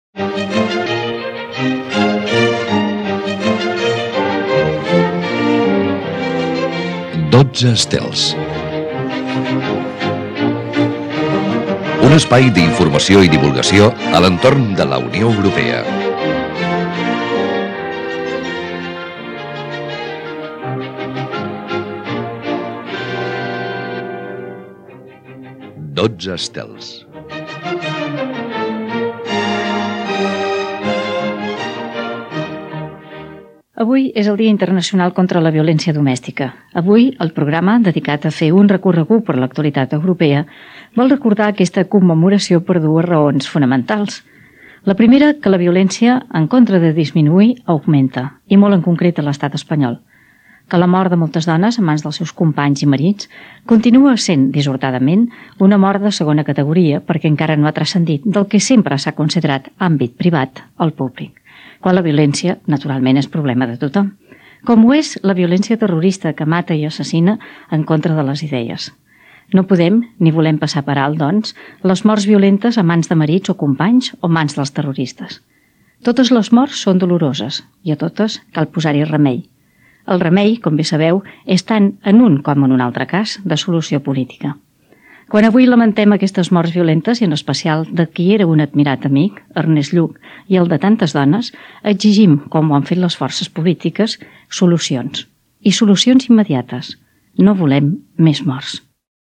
12 estels: editorial - Ràdio 4, 2000